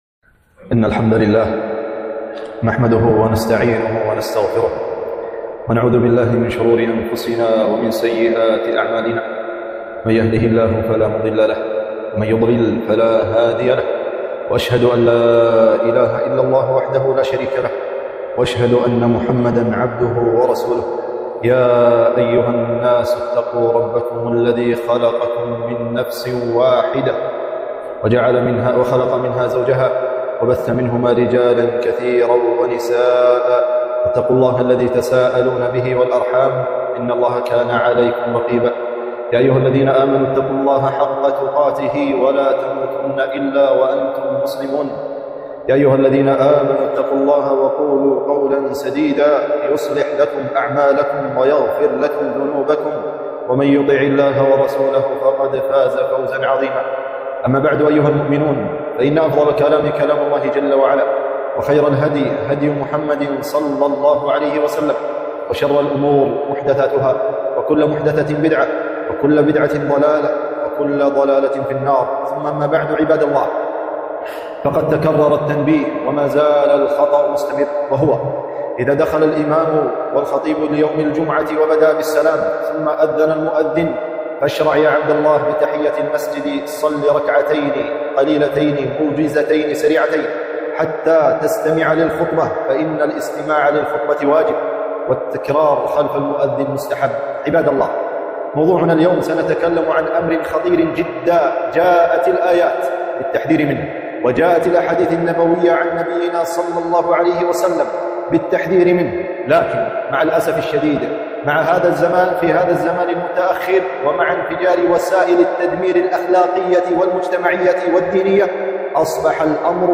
خطبة - غض البصر